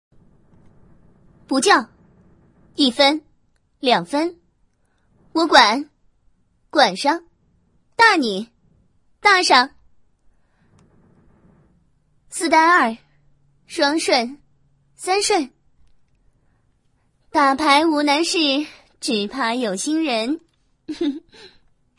斗地主御姐语音包音效免费音频素材下载